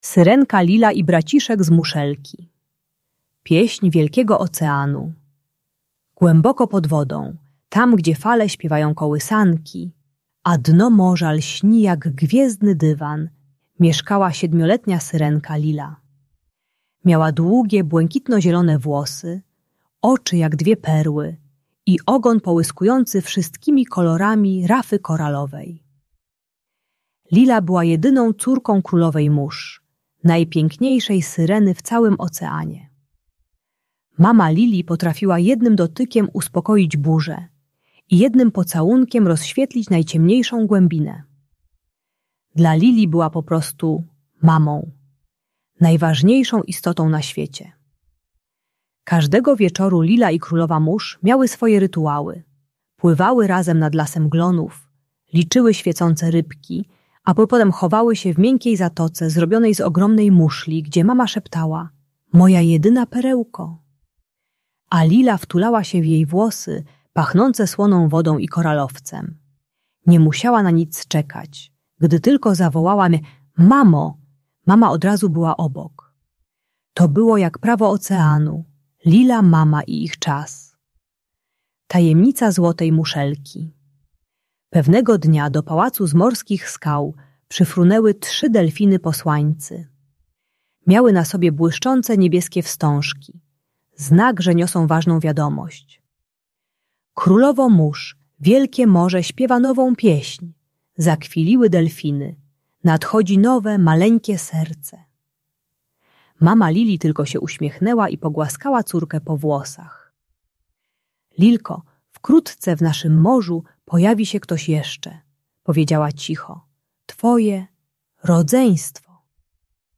Opowieść o Syrence Lili i Braciszku z Muszelki - Rodzeństwo | Audiobajka